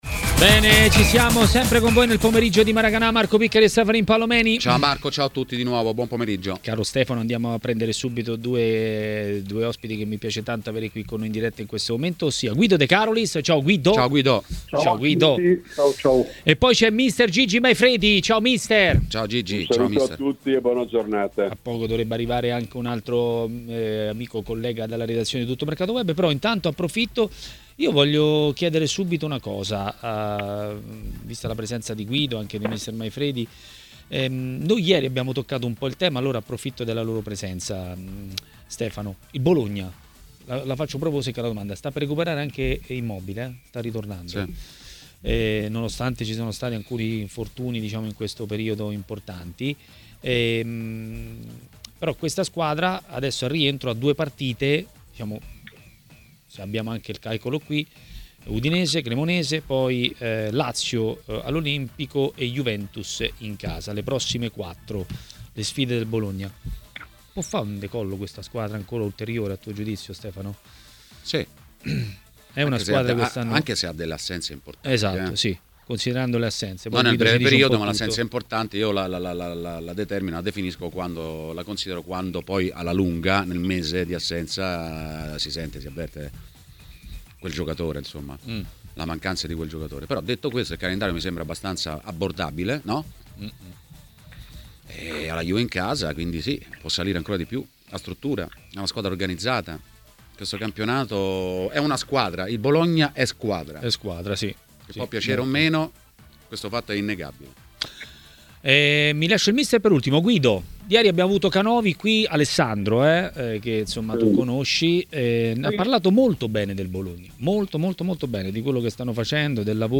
L'opinionista ha parlato ai microfoni di Tmw Radio : " Credo che per un allenatore sia facile calarsi nella situazione di Conte.